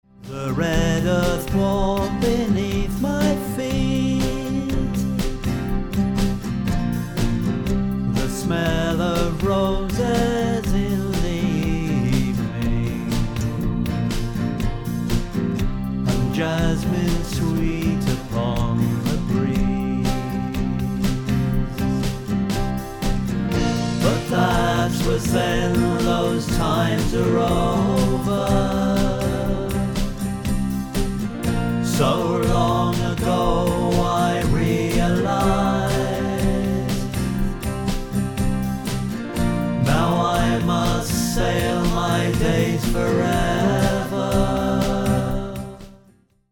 demo